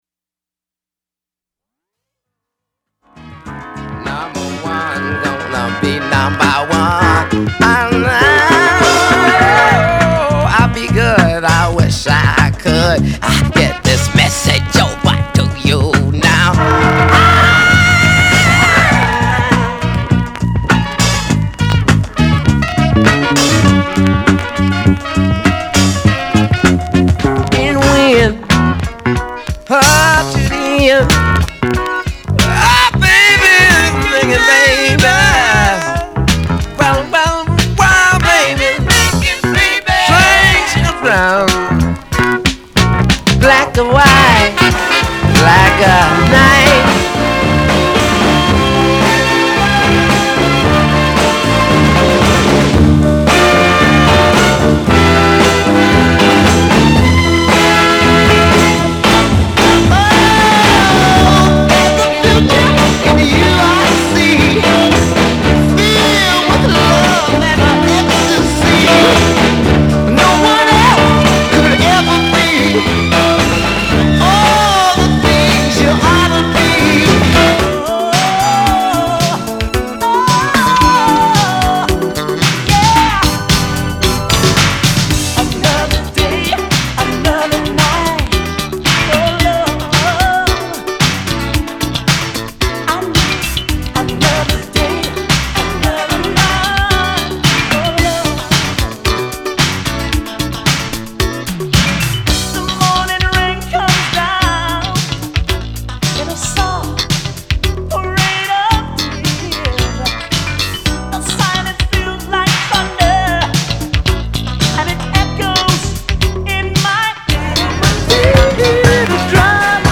/盤質/両面やや傷あり/US PRESS